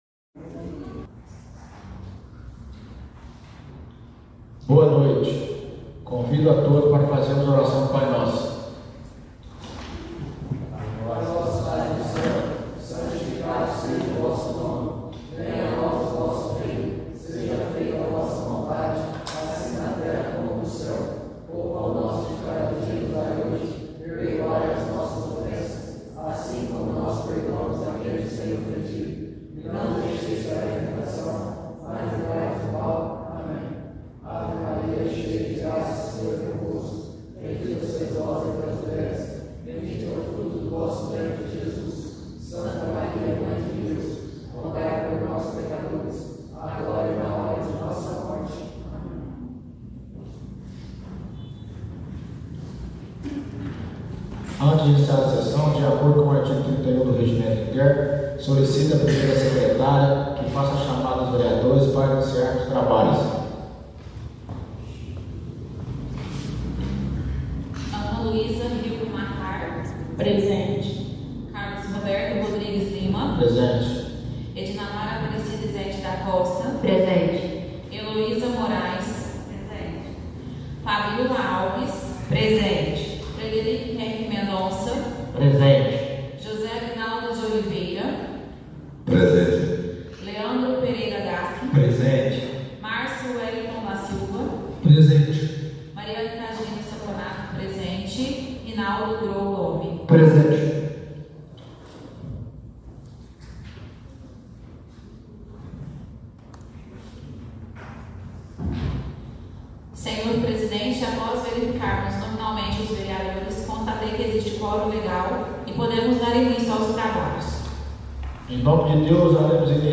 Áudio da Sessão Ordinária 03/02/2025